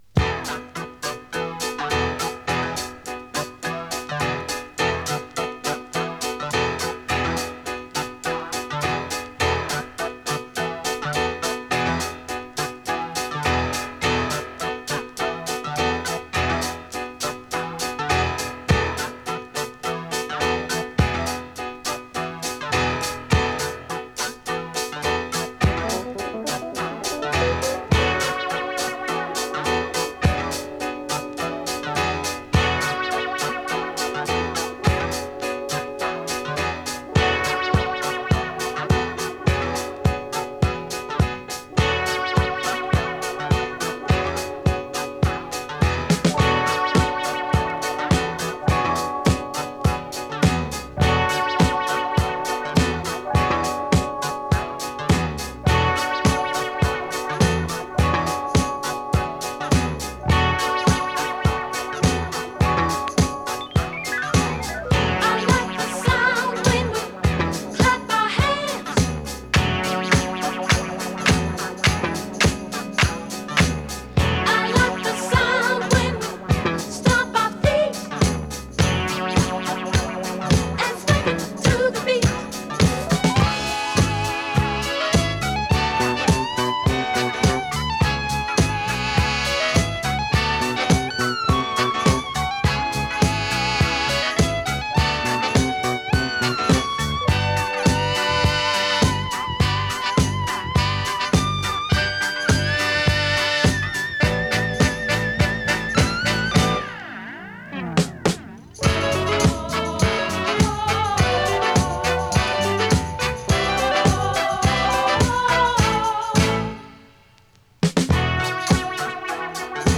SOUL FUNK
どっしり重いリズムと妖し気な雰囲気が堪らないガラージュ・クラシック！